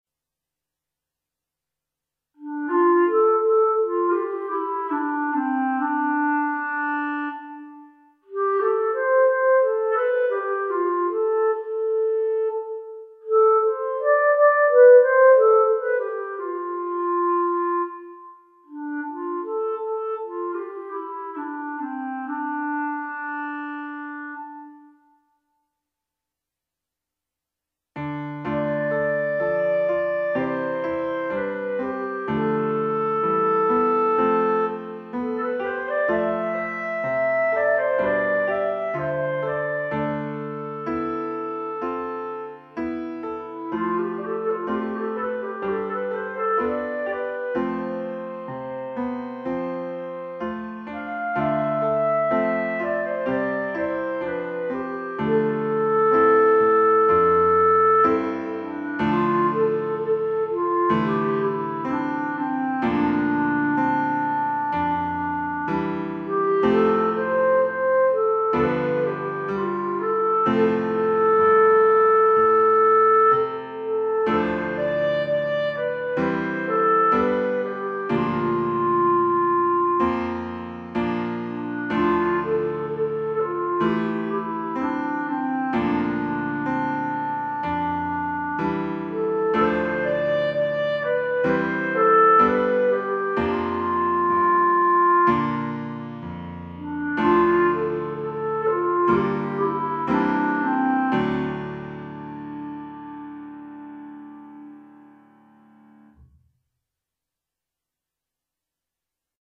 Instrumentation: C, Bb, Eb, pno,
instrument with pno accompaniment.